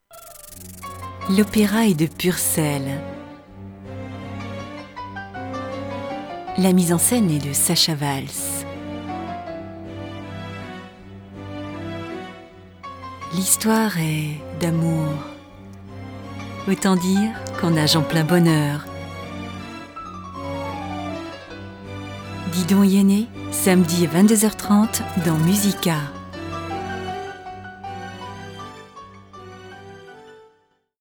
Voix médium, séduisante, s'adaptant à tous vos projets, Home studio Pro, travail sérieux, livraison rapide.
Sprechprobe: Sonstiges (Muttersprache):
French native female voice, warm, elegant, seduisant, accent.